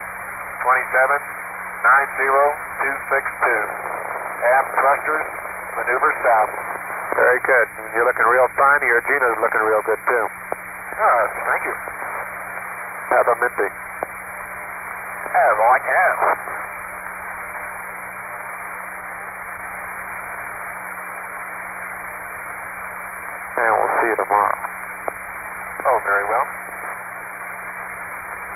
At the end of the Gemini series, a compilation tape of Carnarvon clips from all the manned missions (with the exception of GT10), was produced.